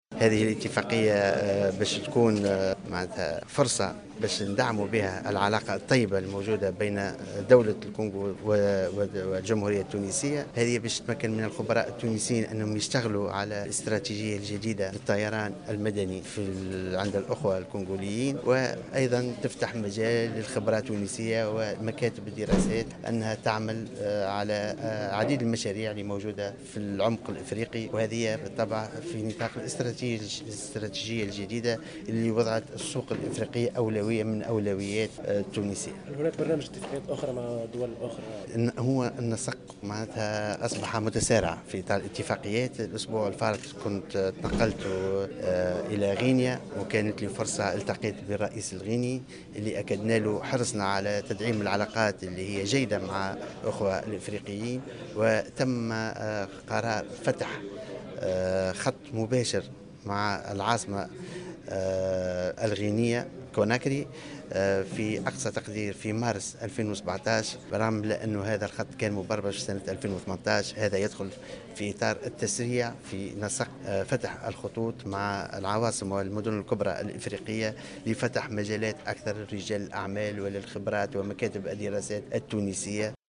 واعتبر وزير النقل أنيس غديرة،في تصريح